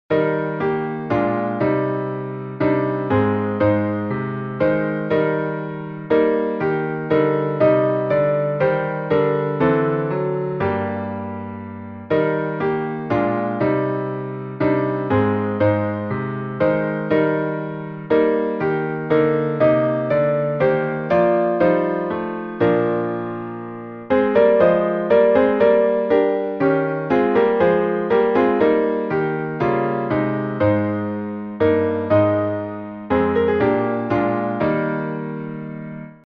Words from Psalm 103 by Henry Frances Lyte (1793-1847), 1834Tune: LAUDA ANIMA (Andrews) by Mark Andrews (1875-1939)Key signature: E flat major (3 flats)Time signature: 3/4Meter: 8.7.8.7.8.7.Public Domain1.